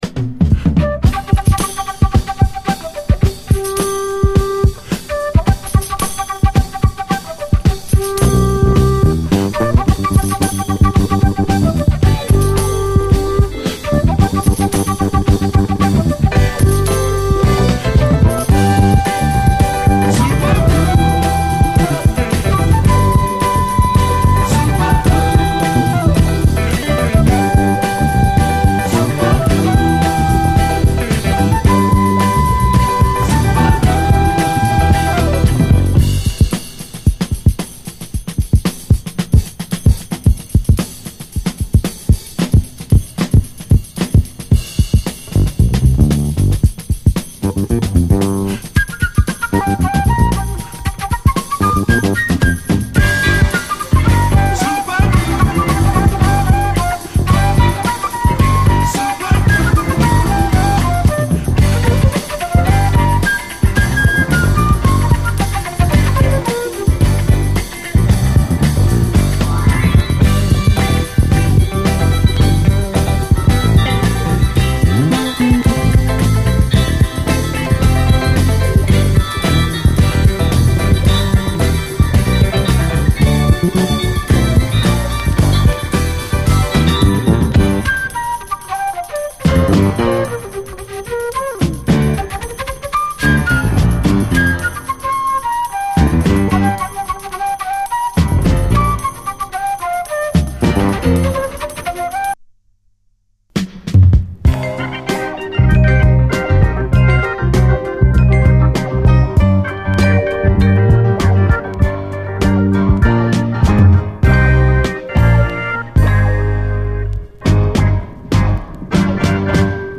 SOUL, JAZZ FUNK / SOUL JAZZ, 70's～ SOUL, JAZZ
フルートの細かい刻みでファンキーなノリを生み出すレアグルーヴ〜ジャズ・ファンク盤！長尺ドラム・ブレイクが現れる